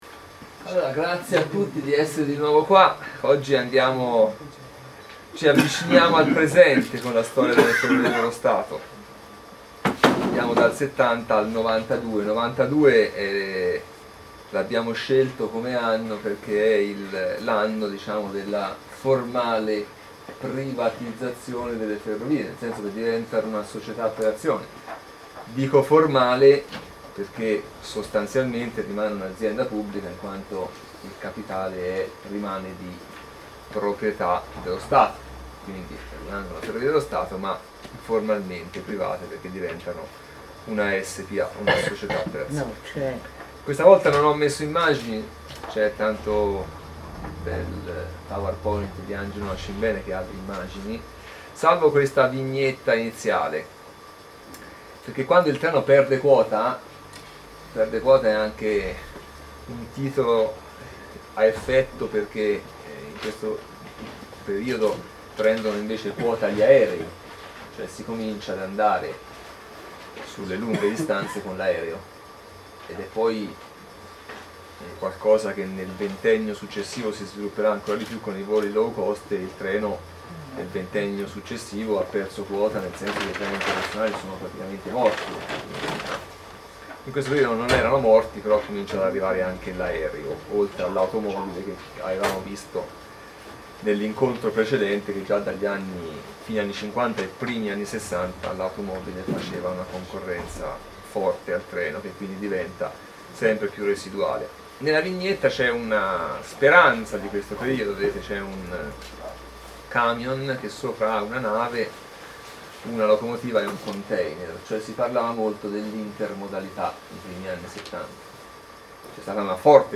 Sono disponibili le registrazioni audio delle conferenze del 25 gennaio 2013: